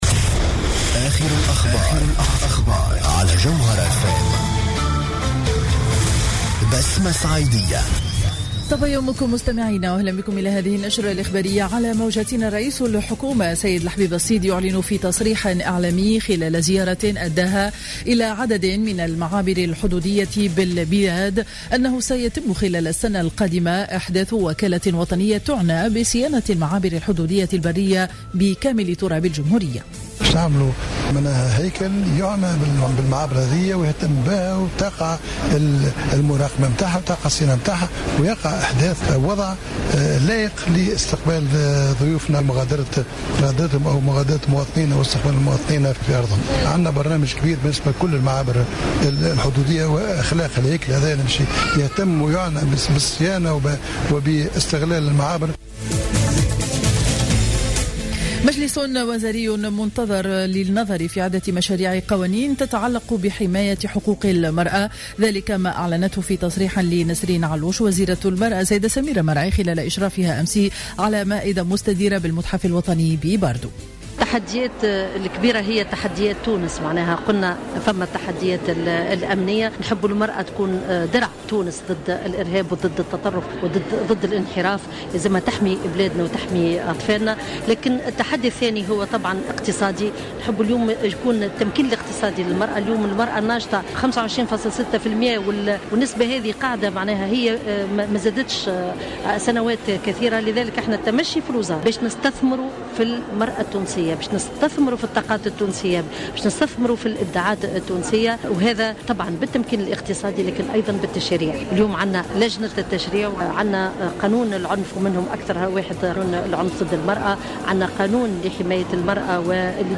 نشرة أخبار السابعة صباحا ليوم الإربعاء 12 أوت 2015